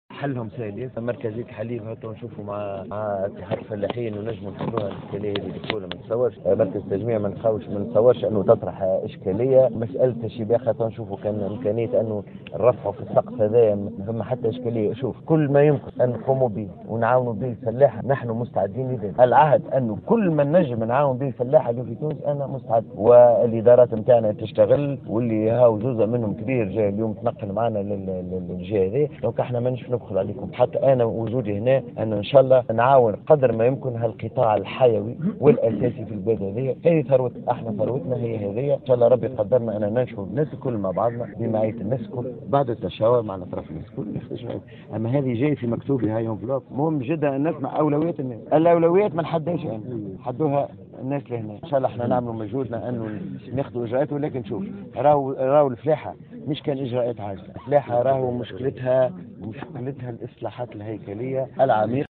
وشدد بالطيب، في تصريح لمراسلة الجوهرة أف أم، على استعداد الوزارة للتفاعل إيجابيا مع جميع مشاغل الفلاحين، على غرار مسألة مركزية الحليب بالجهة، مشيرا إلى أن القطاع يحتاج، بالإضافة إلى الإجراءات العاجلة، جملة من الإصلاحات الهيكلية العميقة.